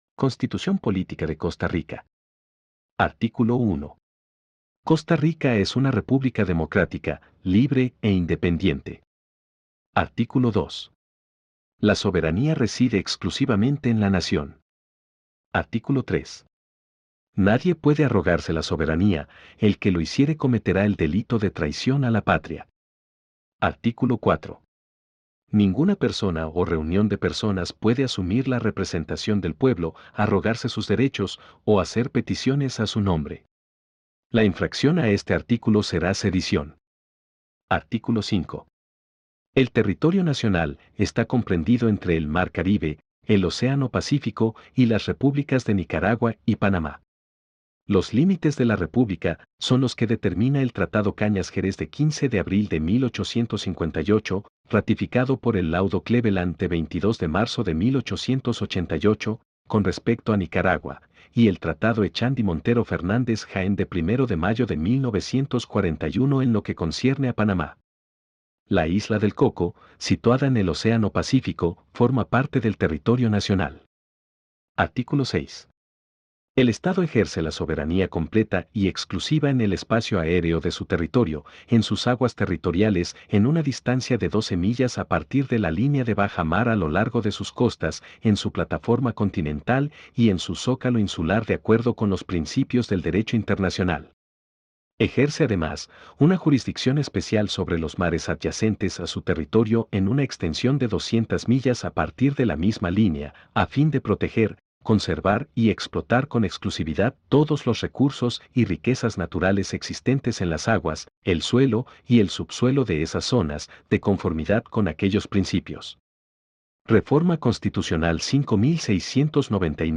Constitución Política de Costa Rica 2021 – Lectura Integral y Comentada